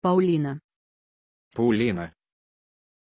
Pronunciation of Paulina
pronunciation-paulina-ru.mp3